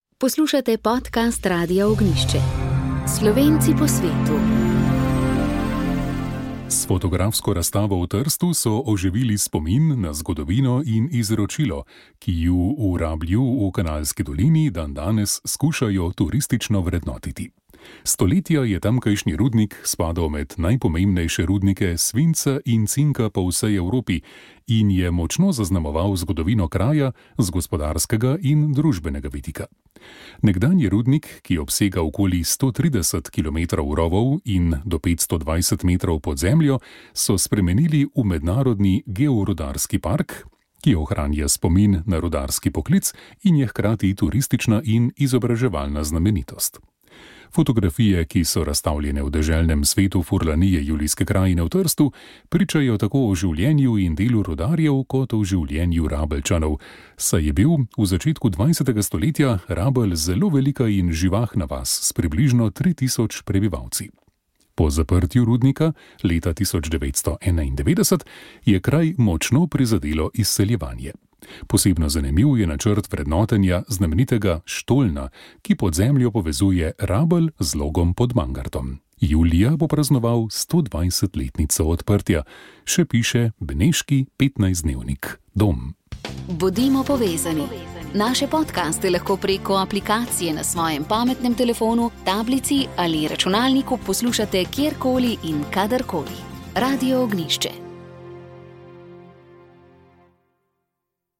Tokrat ste lahko prisluhnili zanimivemu pogovoru z dvema gostoma